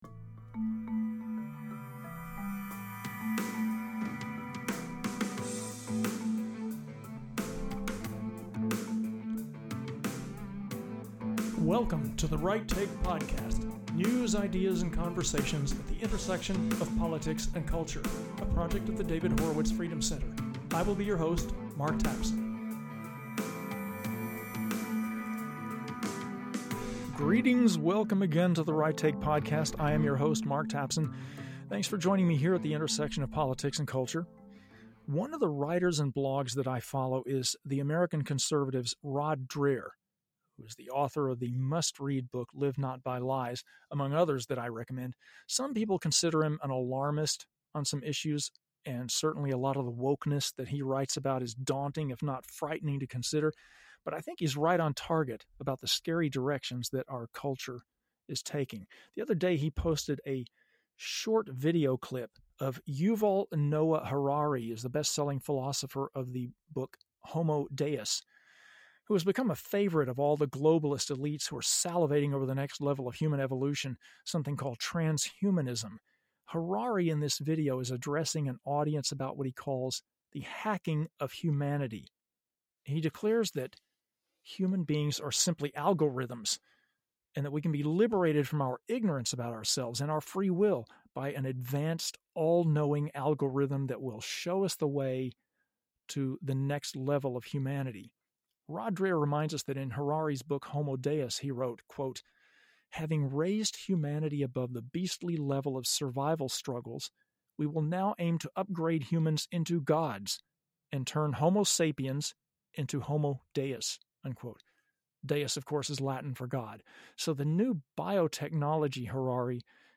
interviews